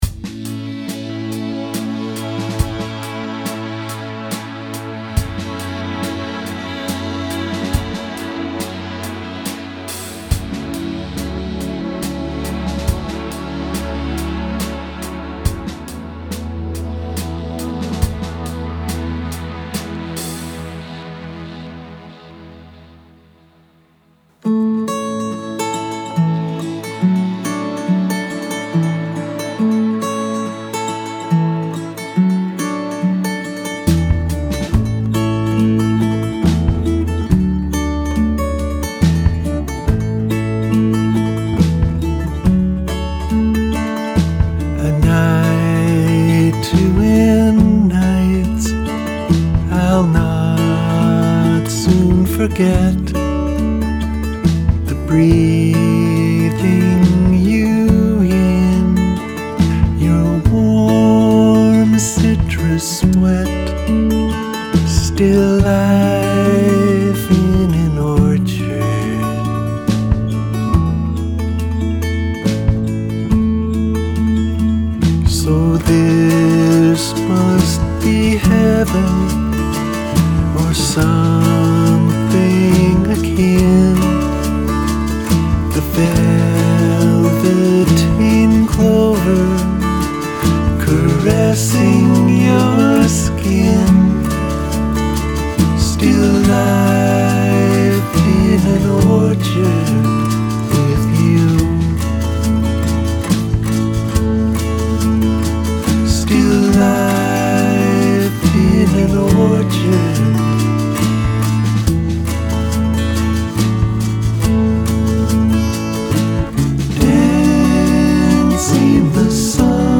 Here I am cooped up in my little studio dreaming about close encounters in an orchard. I attempted "a little '60s poetry" a la Donovan or Leonard Cohen.